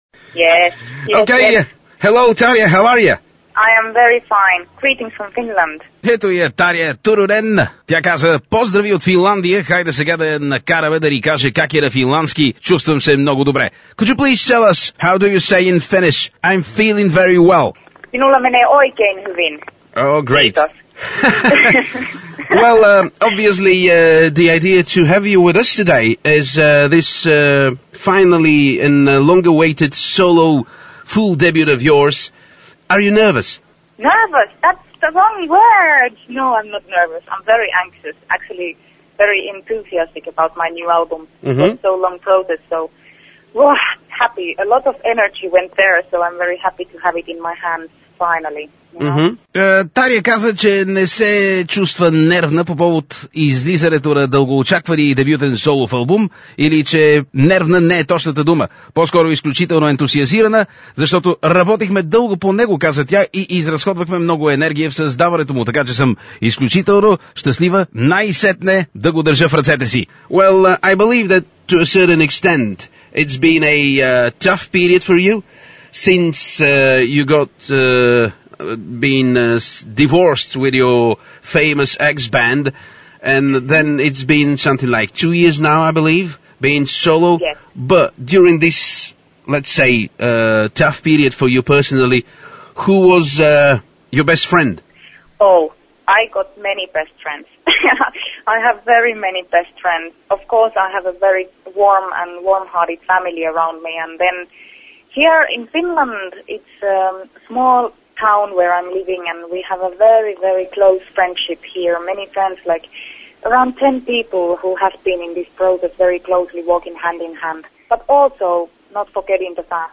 Страхотно интервю дадено от Таря специално за Българското Рок&Метъл Радио-Тангра